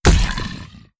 SFX
Death5.wav